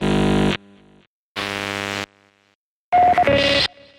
техника звуки скачать, слушать онлайн ✔в хорошем качестве